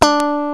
_GUITAR PICK 4.wav